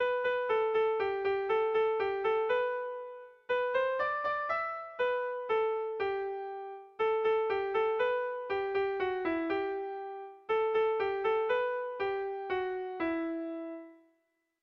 Erromantzea
Doinu xarmanta.
AB